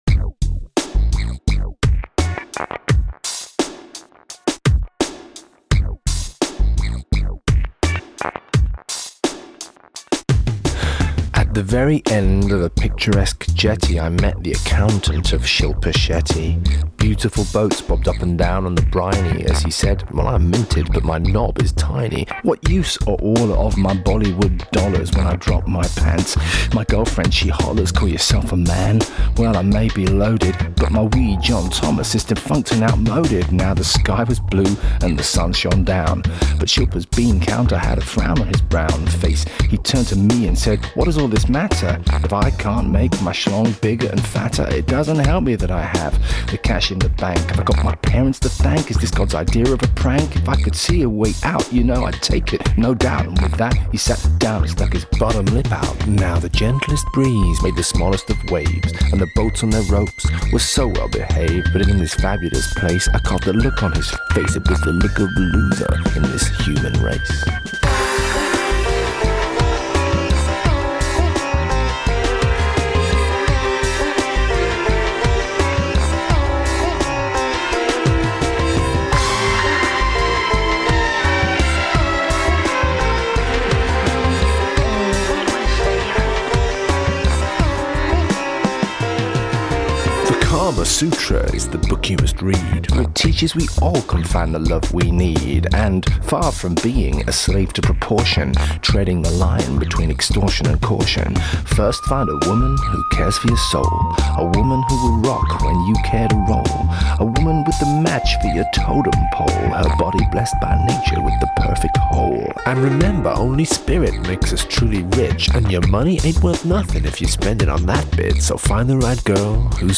In this slow-tempo sardonic rap, the accountant of Shilpa Shetty is obsessed with the size of his manhood, having paid too much attention to spam emails, and it takes a rare moment of enlightenment and a reminder of the advice of the Kama Sutra to restore his sense of proportion – almost.
but here is it restored to it’s proper languid pace.